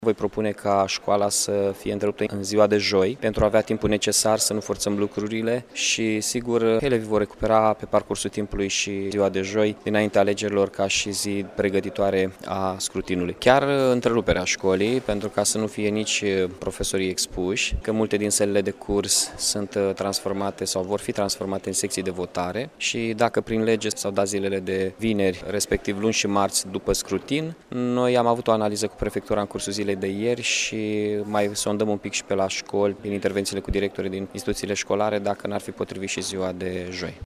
Edilul a subliniat faptul că se doreşte ca în acest fel să fie protejaţi profesorii şi elevii de orice posibilitate de infectare de virusul Sars CoV2: